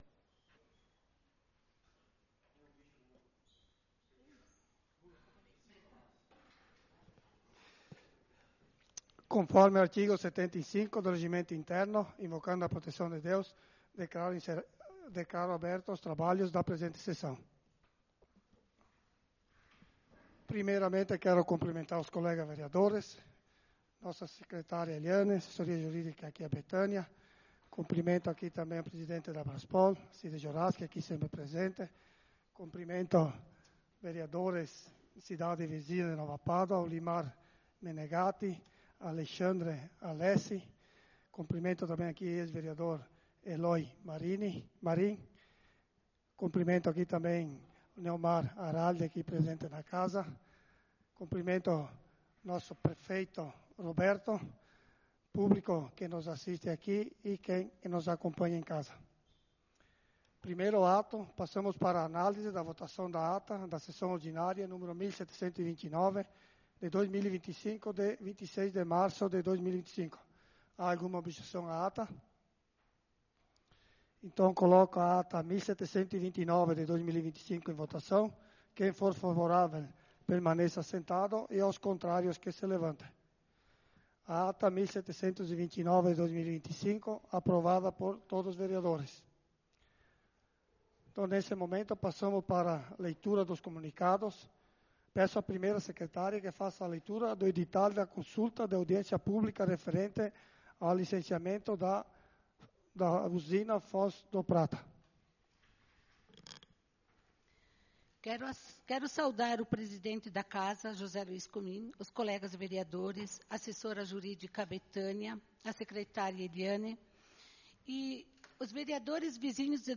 Sessão Ordinária do dia 02/04/2025